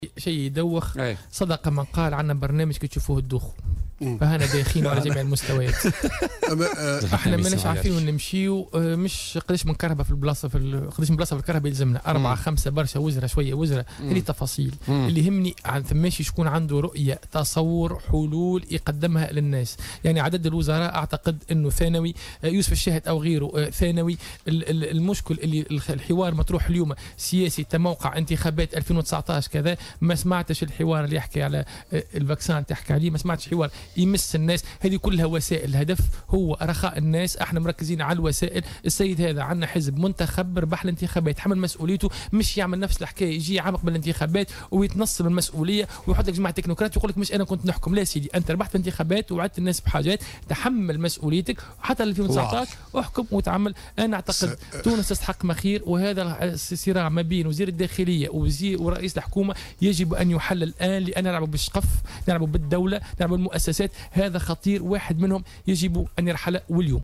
ضيف بوليتكا